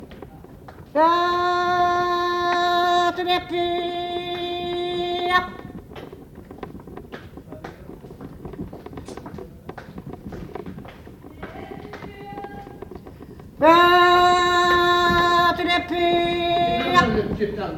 Genre : chant
Type : cri de métier
Aire culturelle d'origine : Liège-ville
Interprète(s) : Anonyme (femme)
Lieu d'enregistrement : Liège
Support : bande magnétique